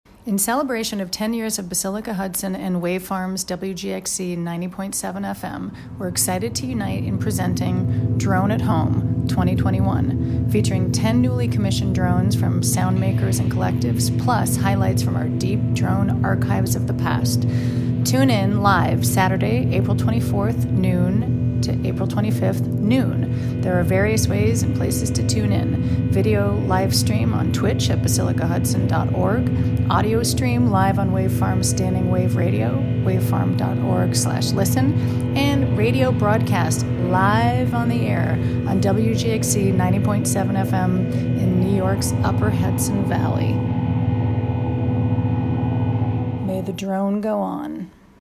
Radio Promo for DRONE AT HOME 2021 (Audio)